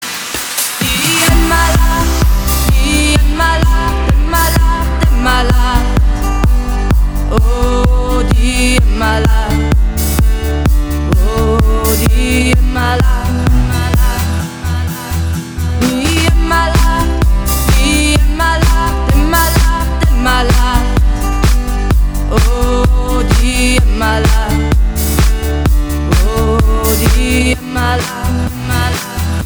Gattung: Moderner Einzeltitel
Besetzung: Blasorchester
Blasmusik mit Gesang
Tonart: As-Dur